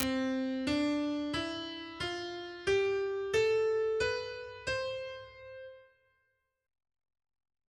C major scale, treble clef.
Diatonic_scale_on_C.ogg.mp3